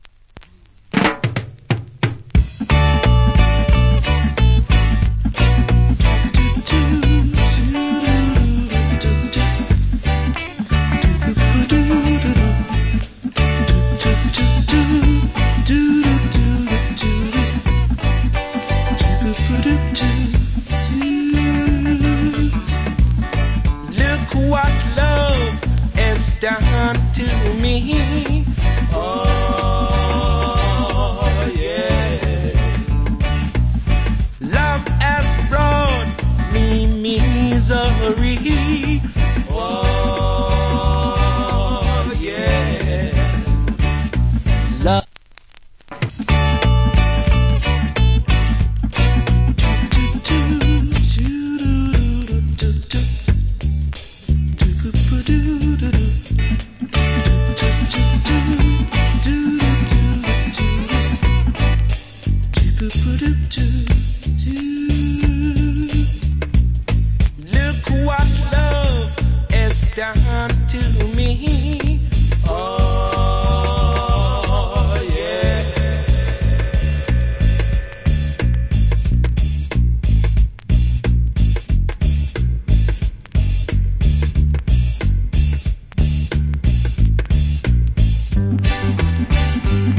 コメント Good Reggae Vocal. / Nice Dub.